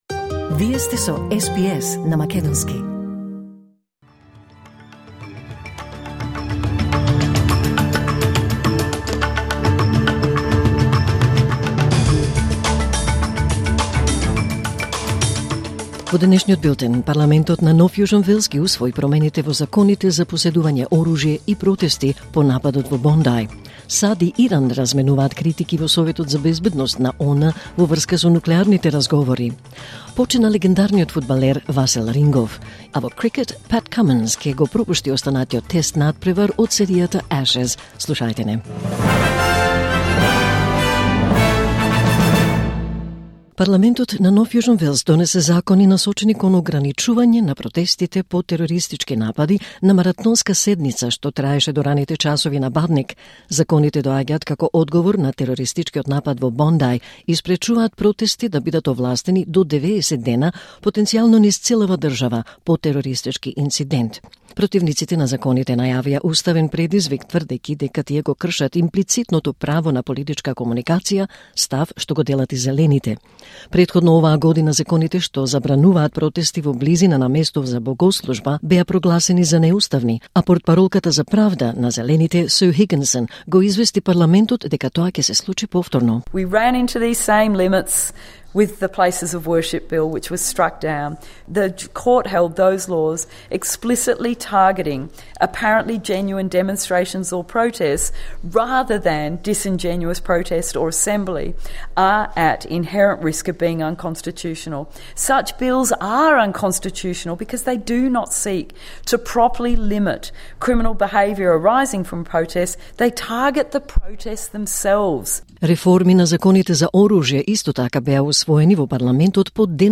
Вести на СБС на македонски 24 декември 2025